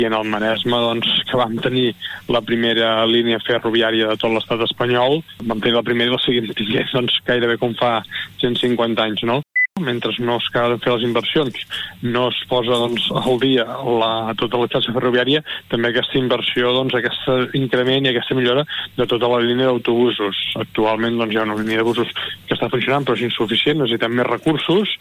En declaracions a RCT, Marc Buch exposa que cal garantir una alternativa eficaç i eficient a un servei ferroviari que continua sent deficient.